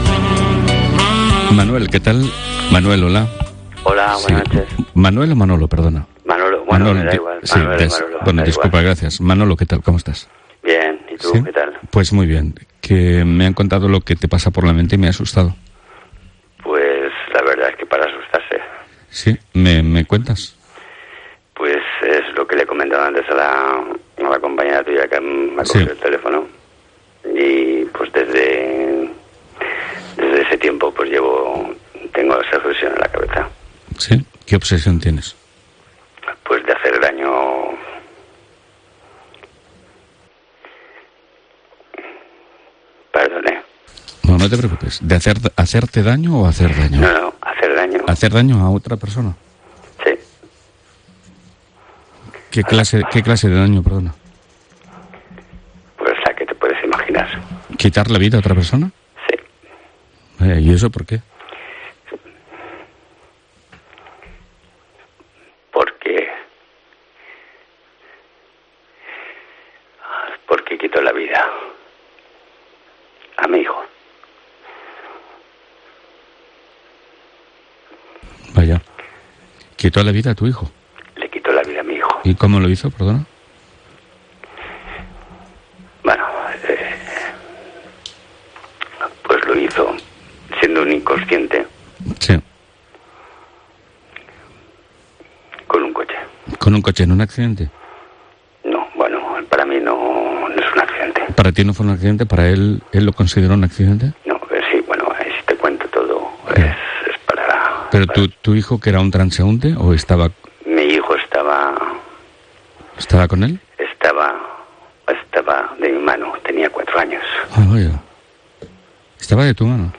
'MOMENTOS' Radio de madrugada